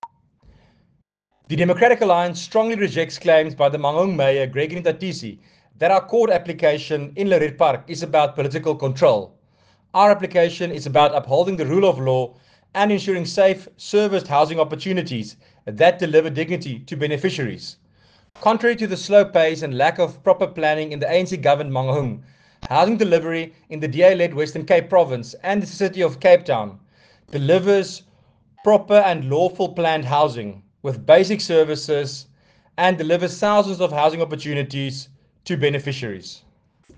English and Afrikaans soundbites